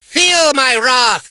mortis_ulti_vo_02.ogg